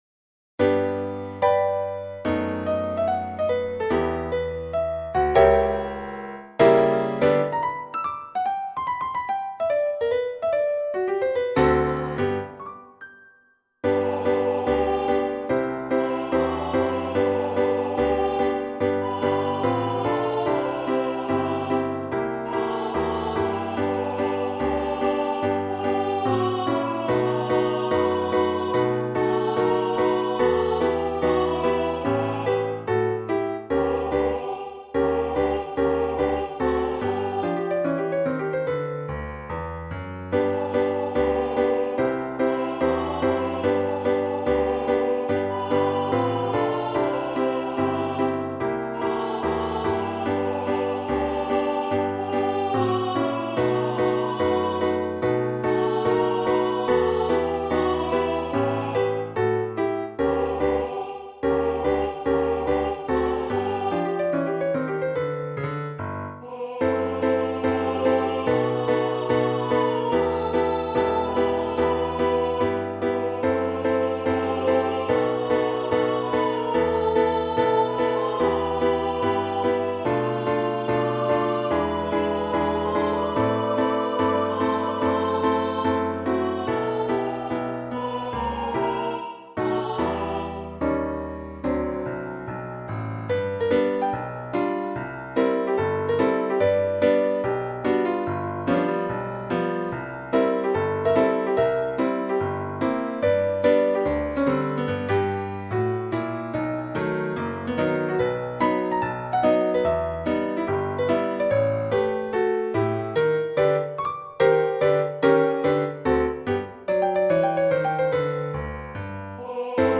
Ноты для фортепиано, хор, партитура